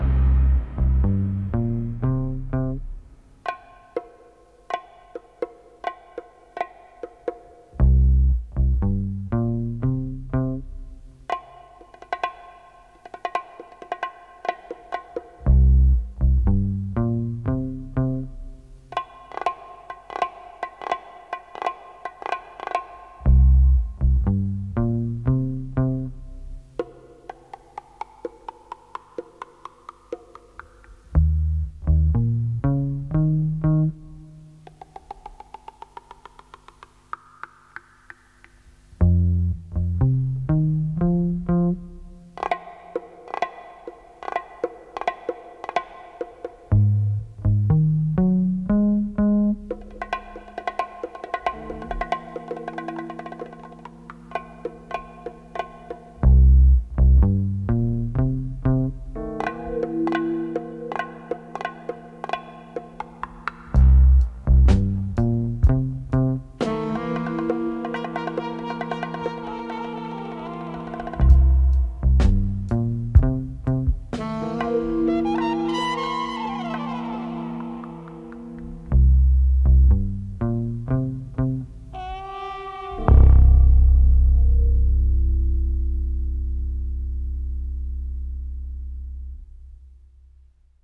Incidental Music from the episode.